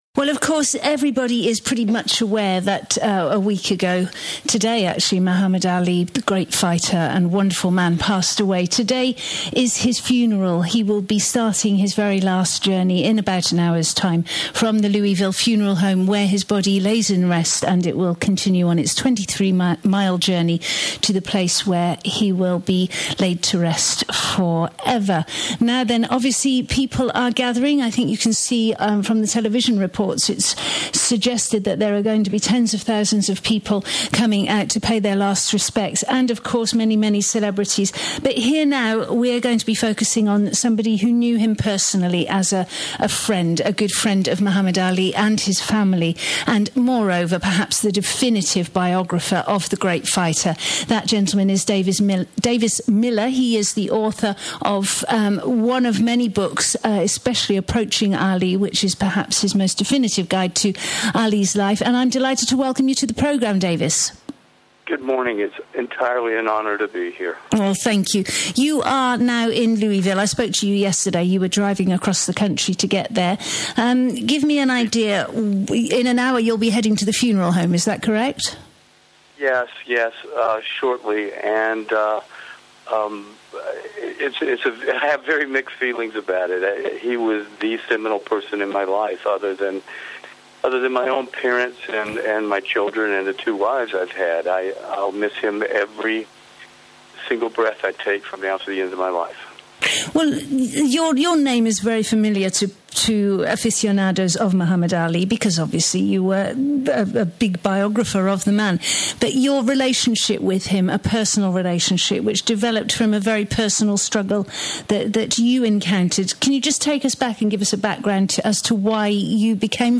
Muhammed Ali's funeral - live broadcast on TRE, from Louisville Kentucky...